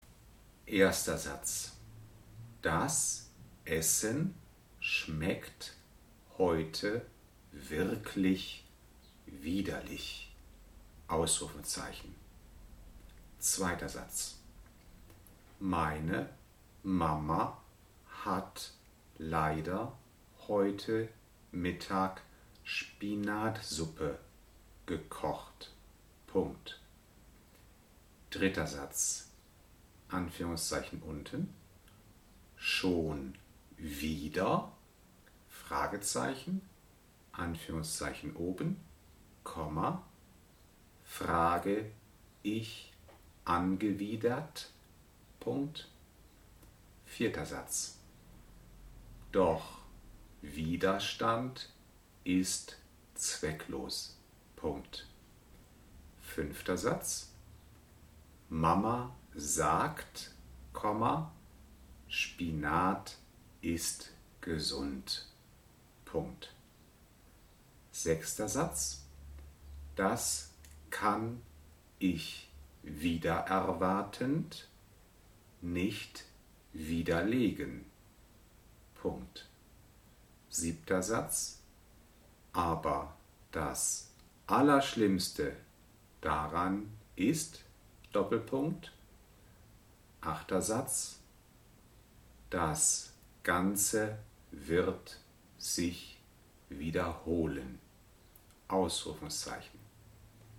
Diktate als MP3
Darin sind die Übungssätze Wort für Wort mit kleinen Pausen diktiert.